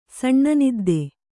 ♪ saṇṇa nidde